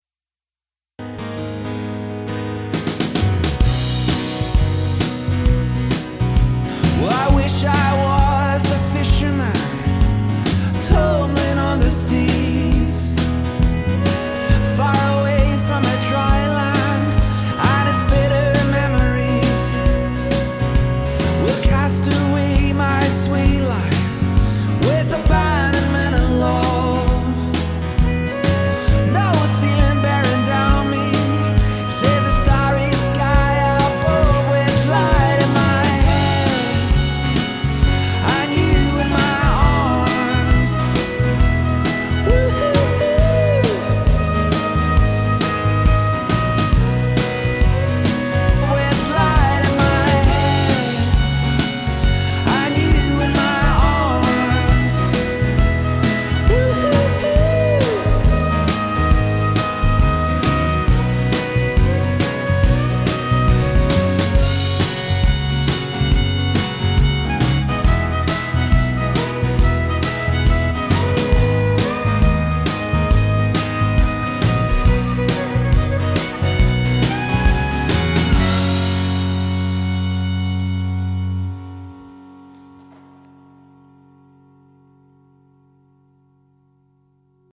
thrilling upbeat folk outfit
wedding band
folk pop outfit
fiddle